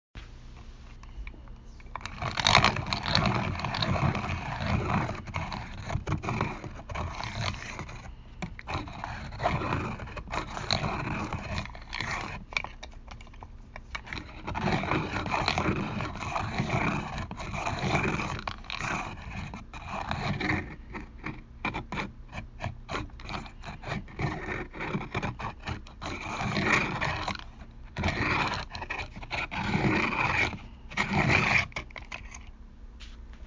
pencil sharpener – Hofstra Drama 20 – Sound for the Theatre
Field Recording 7
Pencil sharpener sharpening a pencil, gears grinding Posted in Field Recording , Uncategorized | Tagged pencil sharpener | Leave a comment |